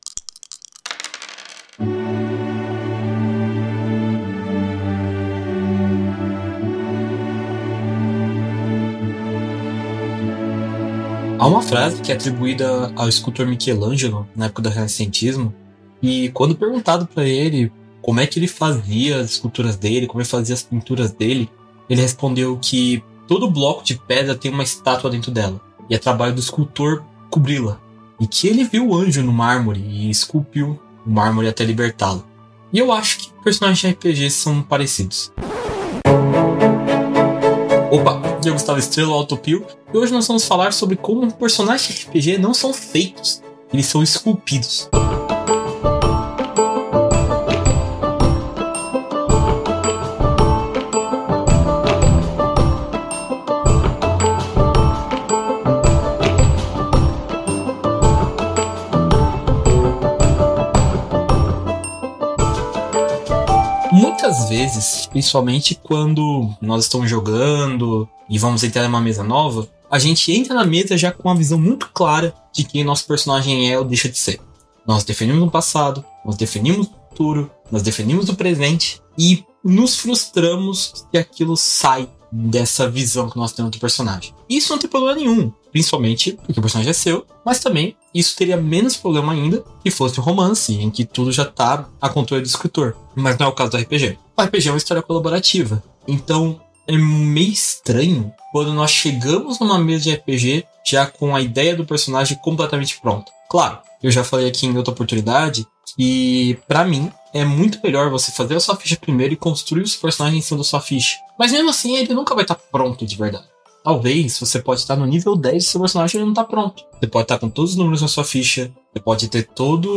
Dicas de RPG Podcasts
Músicas: Music by from Pixabay